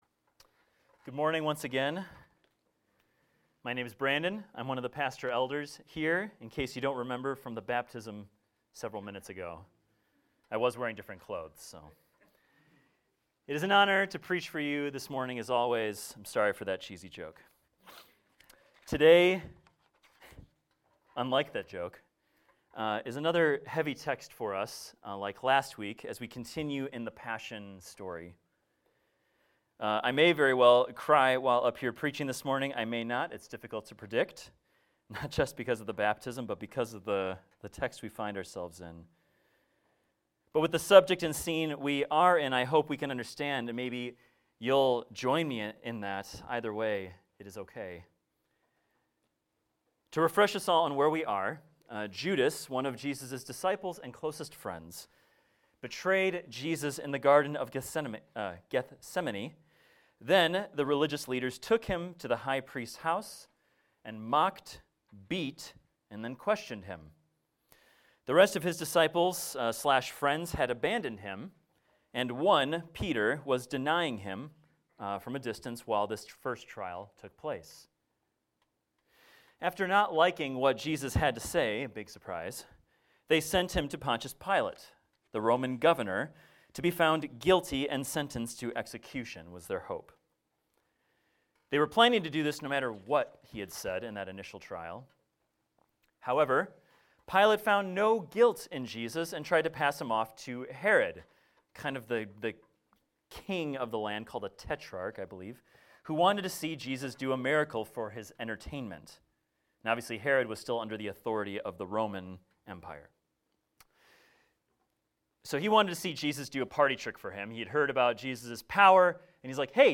This is a recording of a sermon titled, "Crucify Him!."